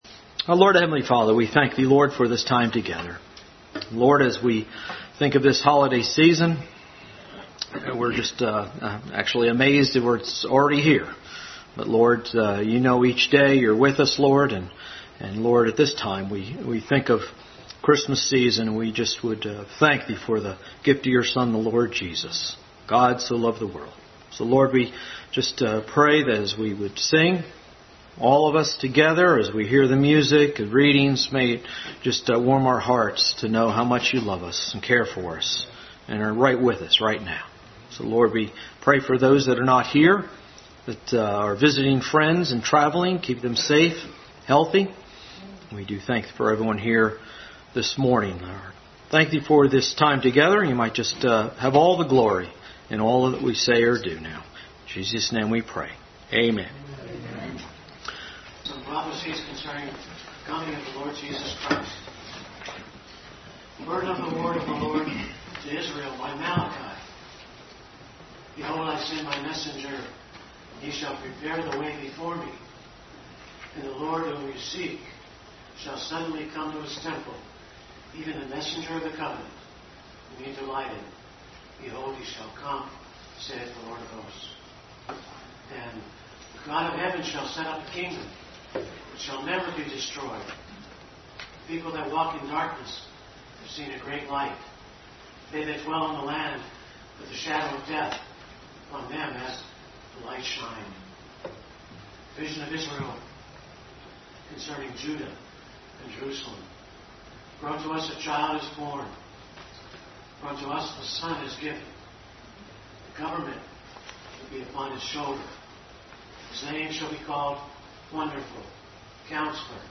December 20, 2020 Christmas Program – 2020 Passage: Various Service Type: Family Bible Hour Christmas Program for 2020.